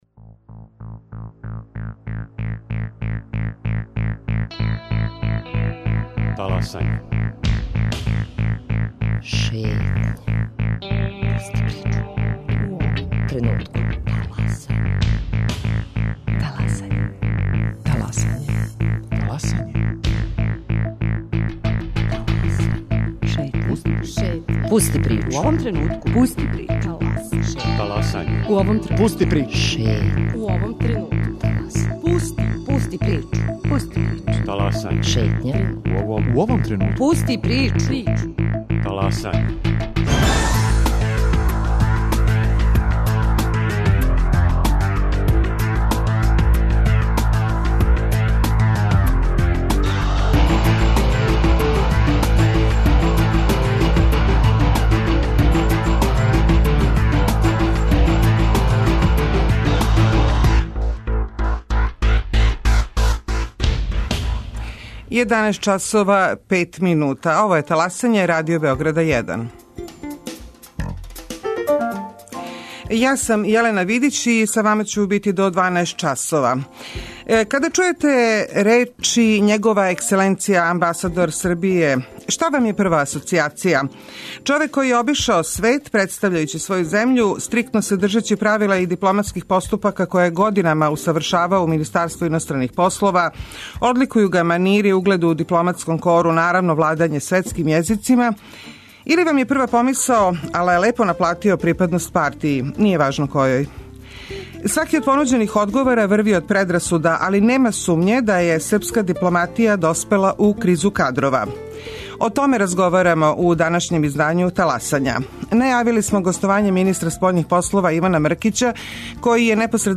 Гости Таласања: Иван Мркић, министар спољних полова и Владислав Јовановић, бивши шеф дипломатије.